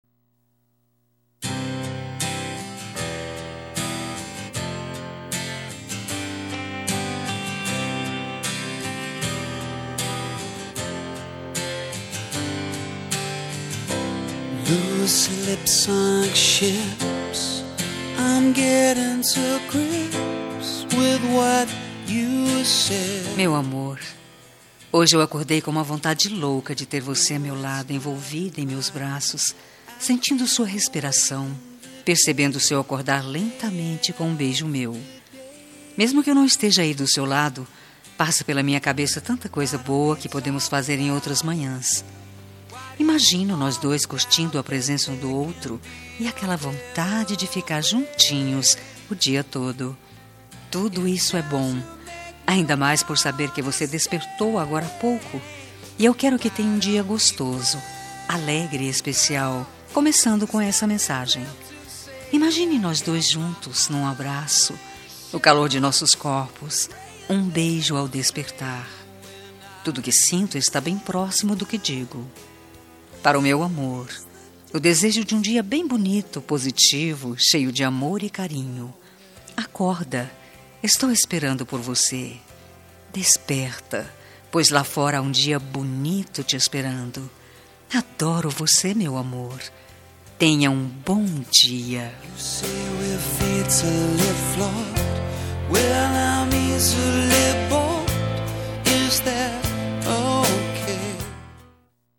Telemensagem de Bom Dia – Voz Masculina – Cód: 6330 – Geral